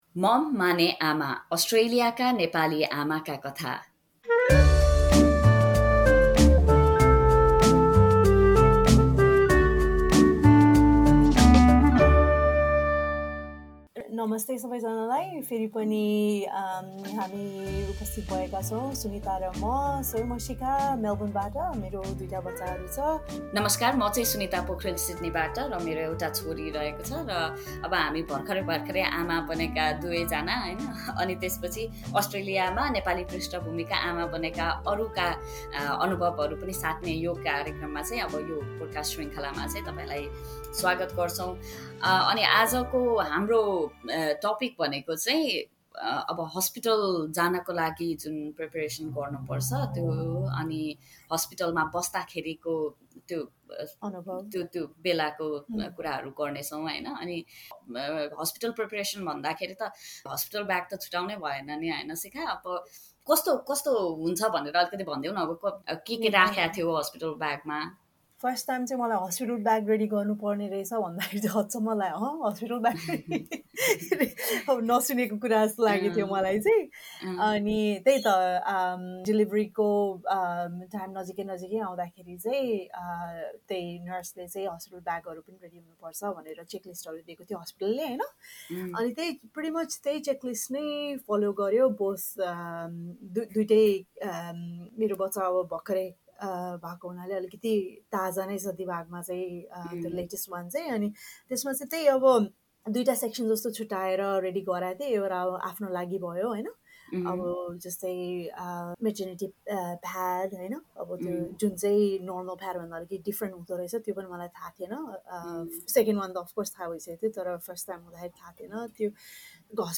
Welcome to a brand new Nepali podcast series on pregnancy, motherhood and everything in between, Mum Mane Ama.